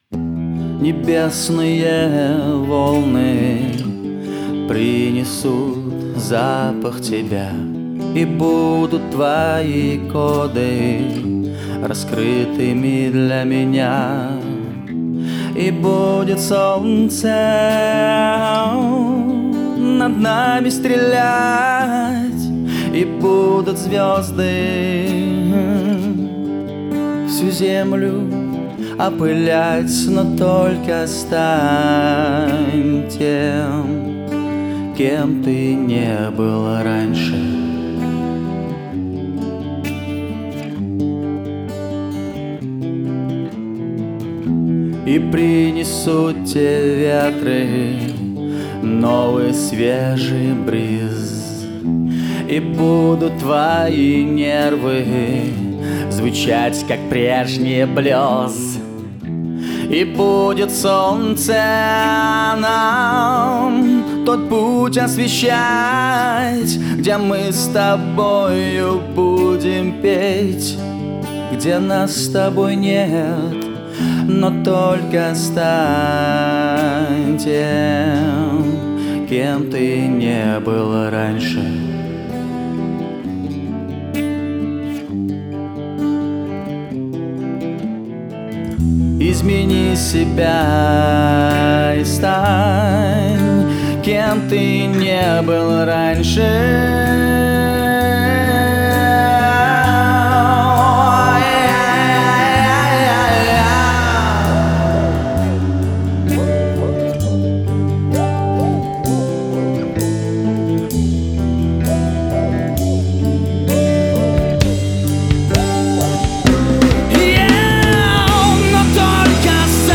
сочетающую лучшее из разных направлений рока